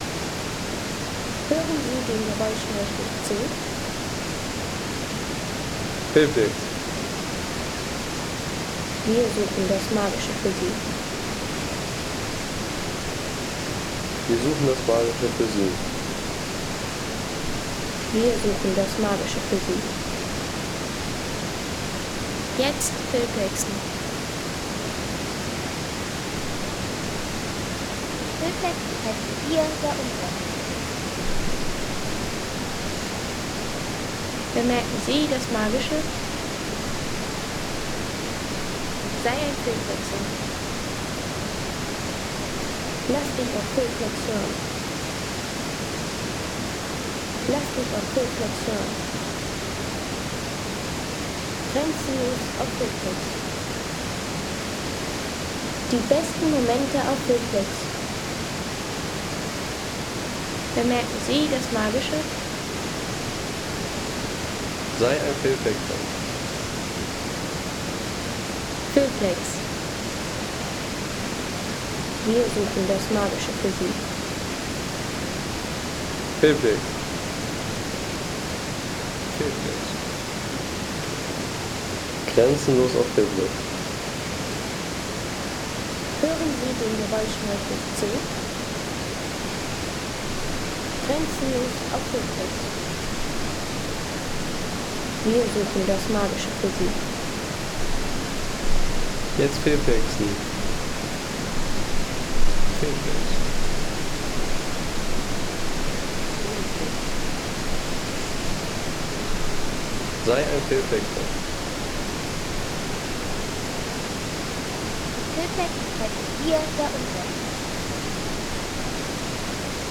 Sastavci Wasserfall Soundeffekt für Film und Naturszenen
Sastavci | Kraftvolle Wasserfall-Atmosphäre aus Plitvice
Kraftvolle Wasserfall-Atmosphäre von Sastavci im Nationalpark Plitvicer Seen. Ideal für Film, Reisevideos, Dokus und immersiven Natur-Hintergrundsound.